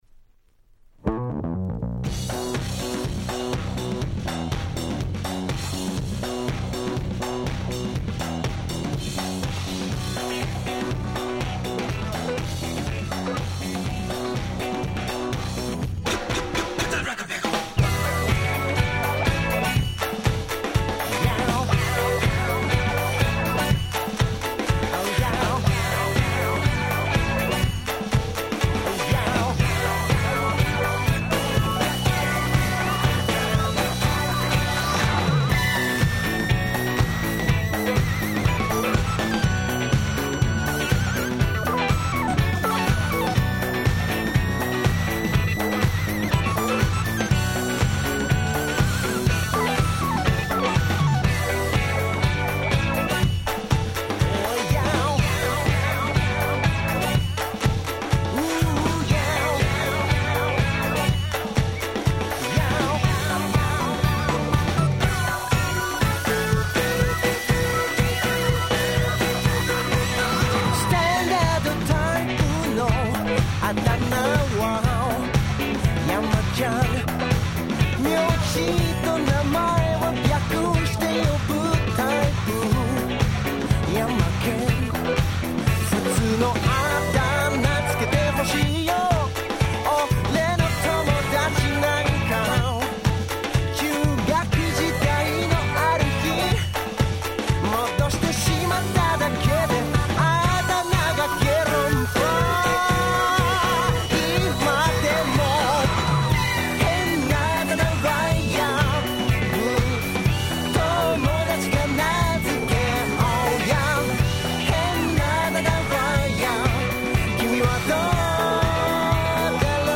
Dance Classicsの往年の名曲達を面白楽しく日本語で替え歌してしまった非常にユーモア溢れるシリーズ！(笑)
Disco ディスコ